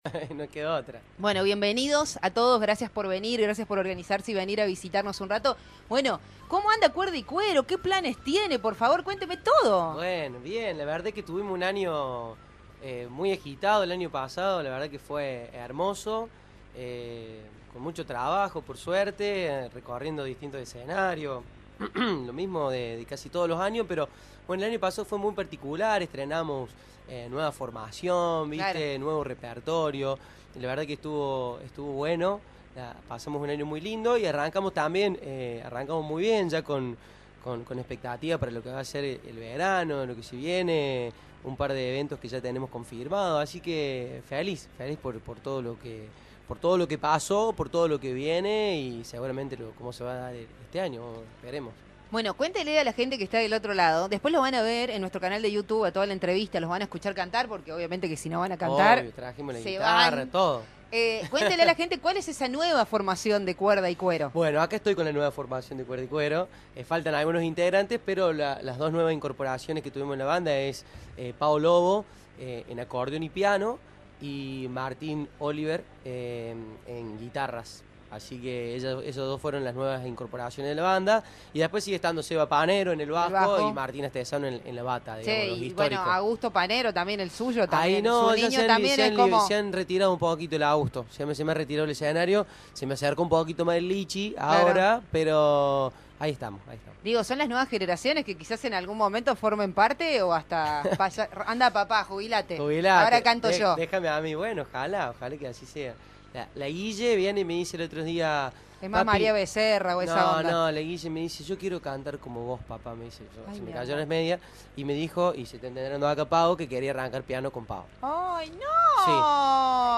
En un ambiente distendido, los talentosos músicos regalaron a la audiencia algunos de sus cautivadores temas en vivo, brindando a los oyentes un adelanto del arte que llevarán en próximos eventos y compartiendo la energía contagiosa de su propuesta musical.